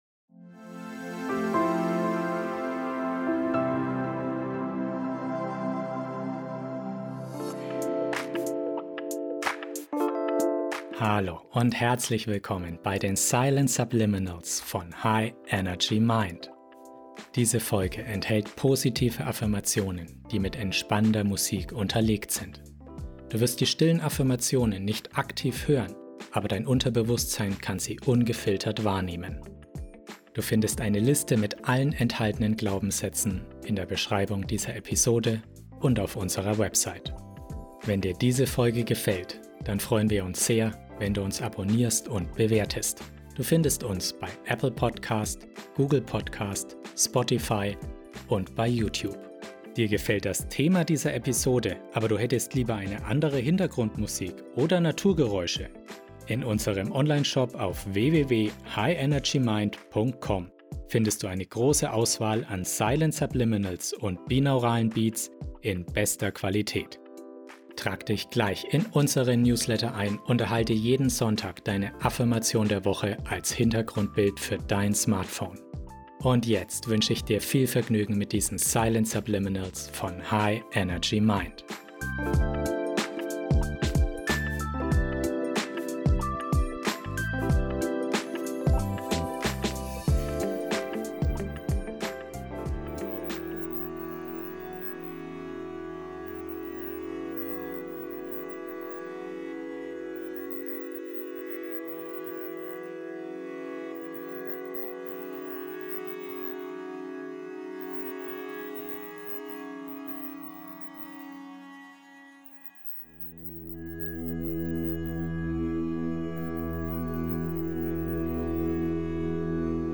Beschreibung vor 4 Jahren Über diese Folge In dieser Folge im Silent Subliminals Podcast bekommst du stärkende Botschaften, die dich darin unterstützen, deine Selbstheilungskräfte zu aktivieren. Die beruhigende 432 Hz Musik begleitet kraftvolle Silent Subliminals, die dein Unterbewusstsein erreichen und die natürlichen Heilkräfte deines Körpers aktivieren können.